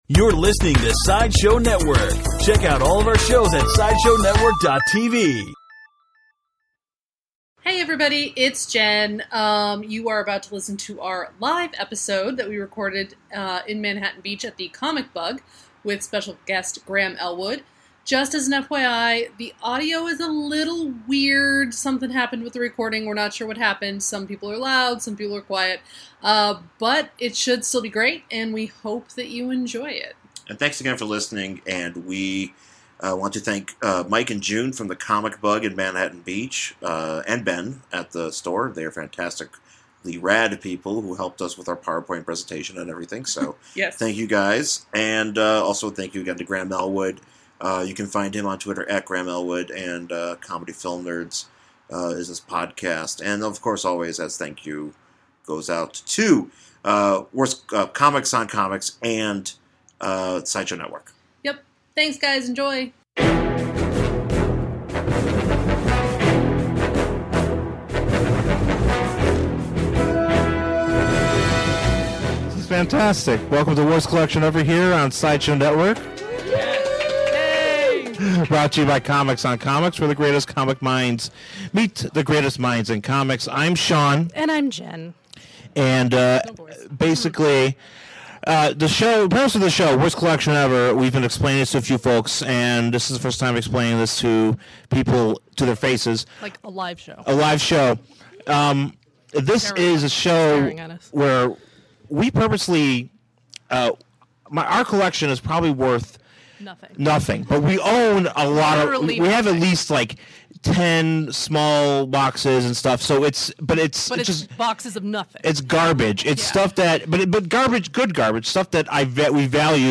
LIVE @ The Comic Bug